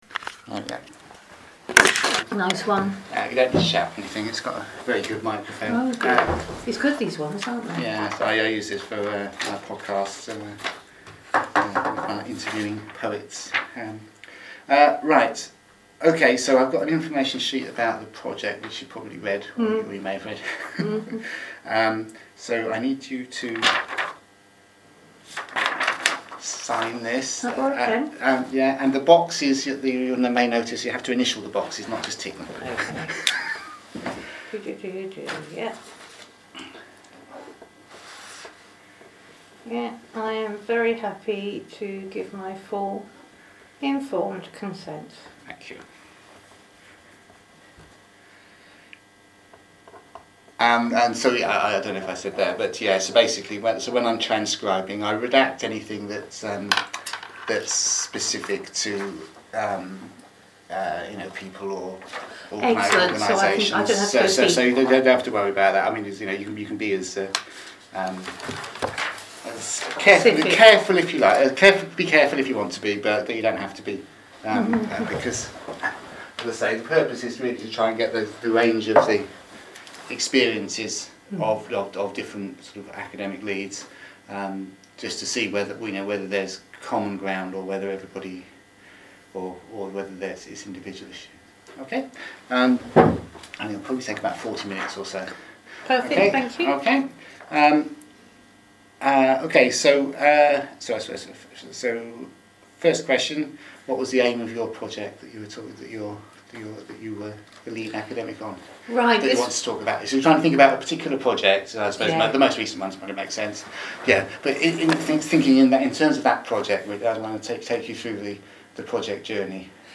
Participant 5 interview (until phone call)